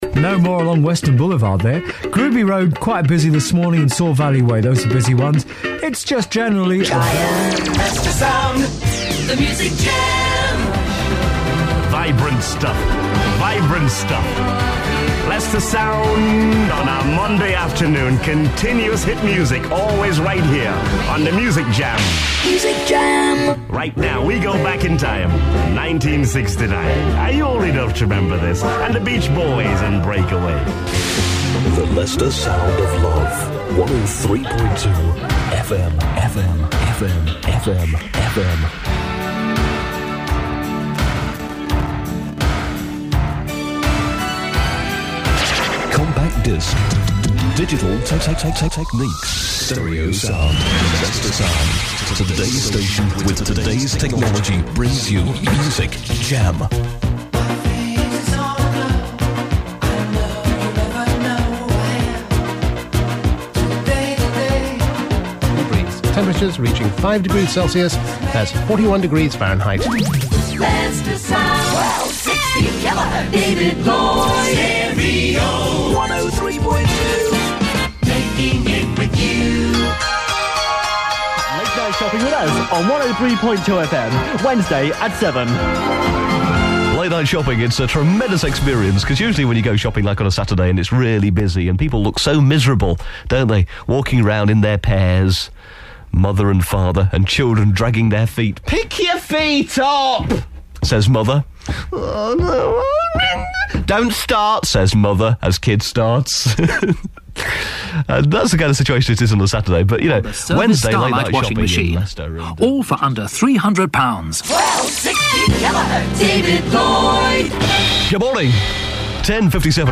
A montage of how Leicester Sound sounded in 1988